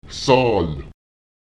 Lautsprecher zál [saùl] die Zahl, das Numerale